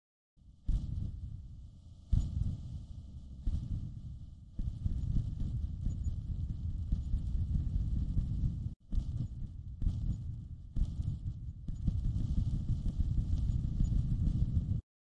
爆炸
描述：一次爆炸 想使用这个声音？
标签： 热潮 爆炸 爆炸
声道立体声